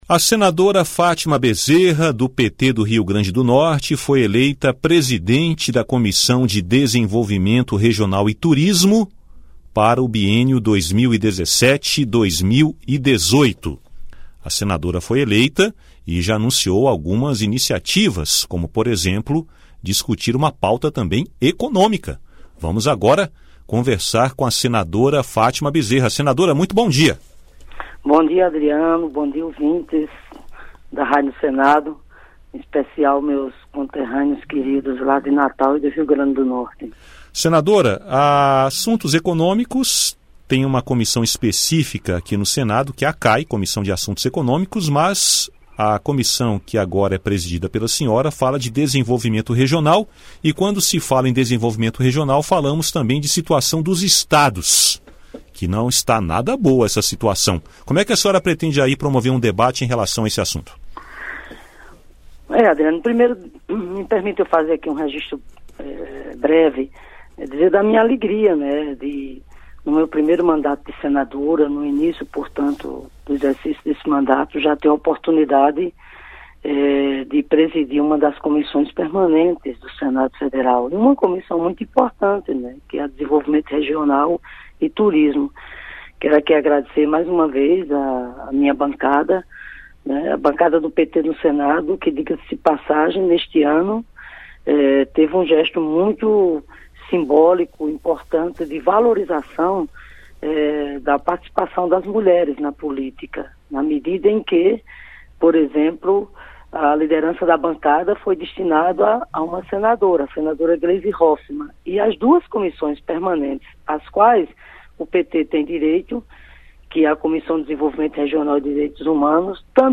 Eleita presidente da Comissão de Desenvolvimento Regional e Turismo (CDR), a senadora Fátima Bezerra (PT-RN) afirmou, em entrevista nesta quinta-feira (16) à Rádio Senado, que já recebeu requerimento para uma audiência pública sobre a cobrança de bagagem despachada nos voos.